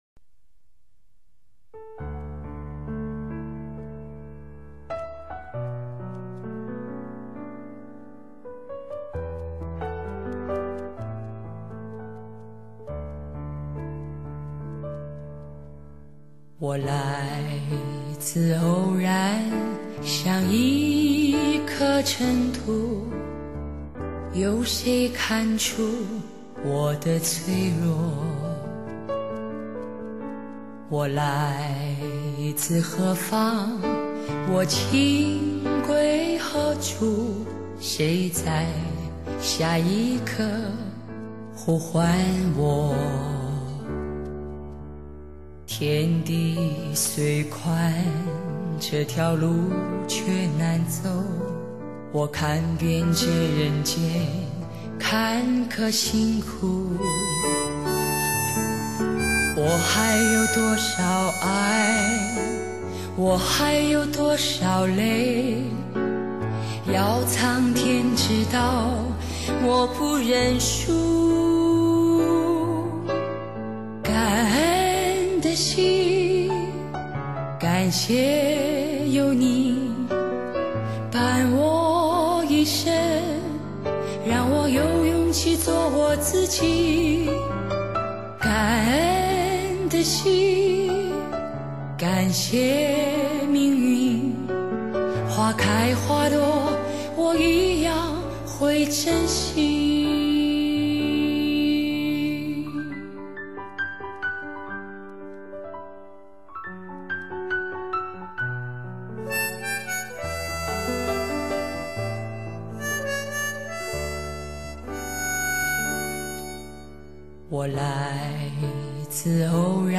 现代发烧，声色入肺，发烧试音新标准，世纪天籁靓声.......